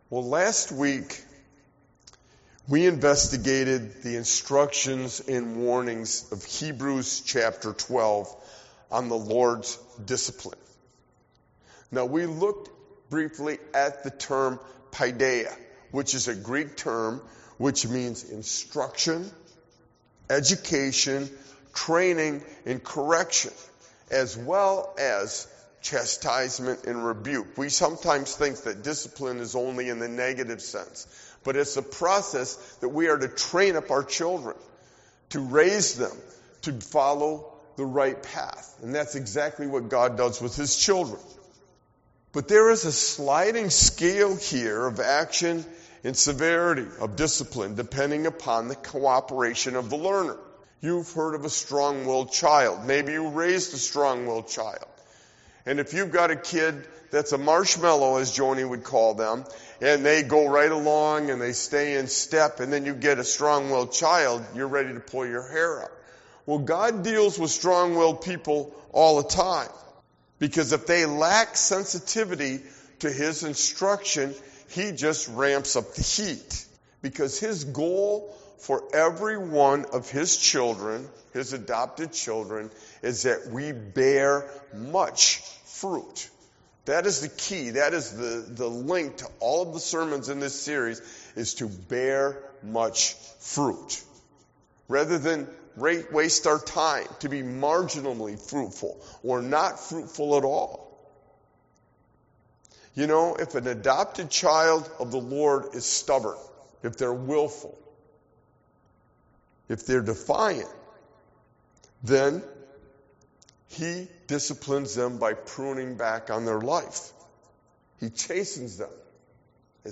Galatians 6:1-10; The final message in our Fruitfulness series focuses on Paul’s parting instruction to the Galatian church. Following his vivid comparison between ‘acts of the flesh’ and ‘fruits of the Spirit’, he concludes with several activities and behaviors that define a fruit bearing child of God.